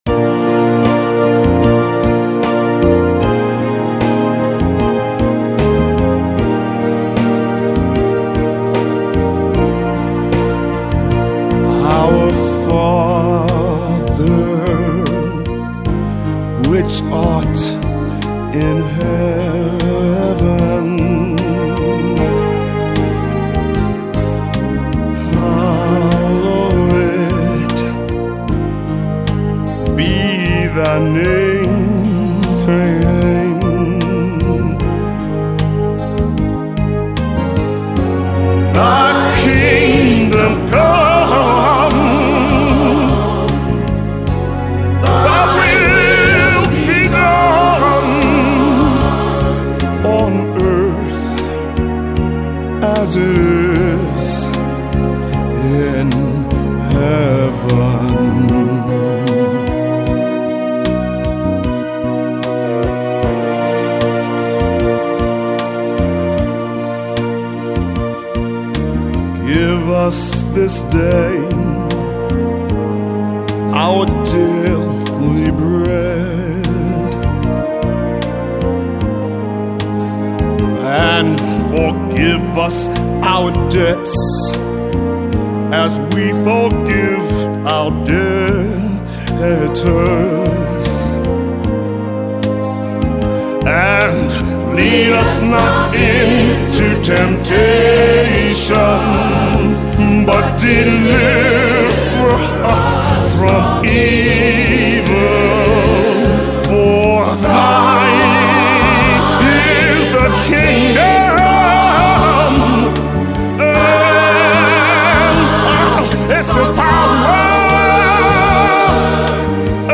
approx song time 2:45   Vocal M/F. Soft Rock Ballad setting.